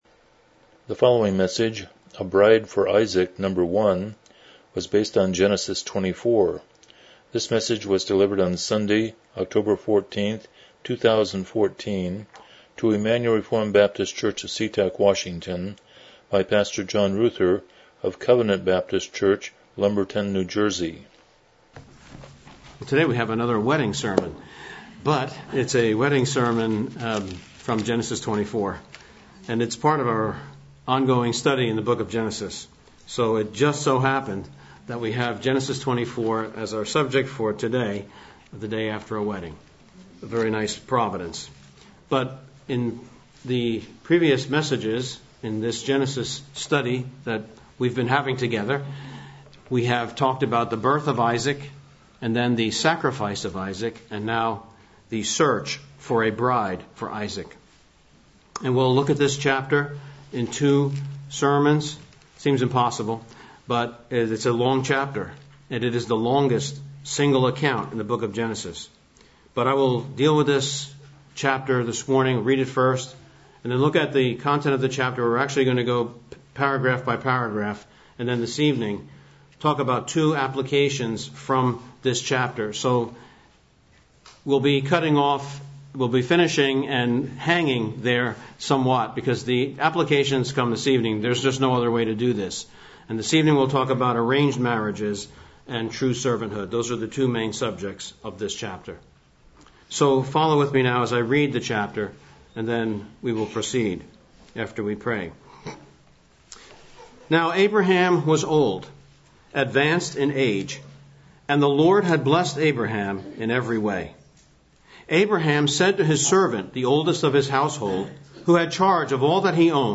Genesis 24:1-67 Service Type: Morning Worship « Songs of Ascent